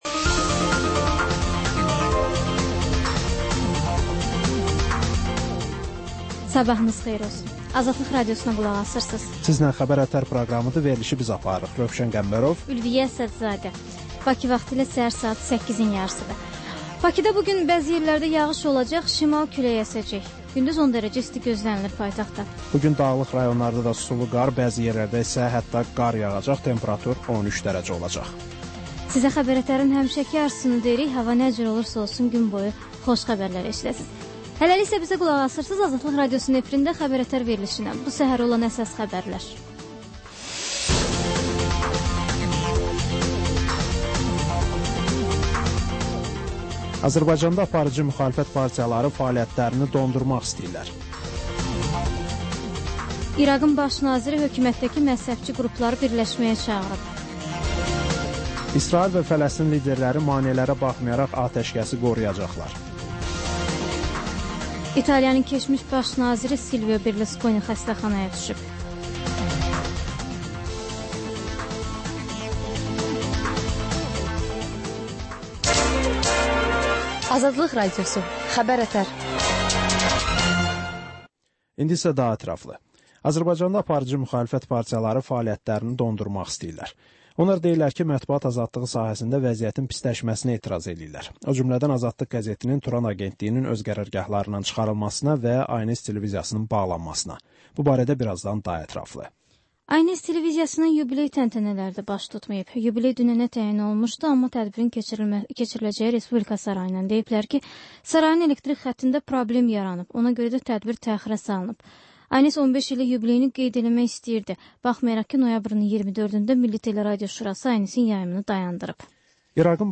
Səhər xəbərləri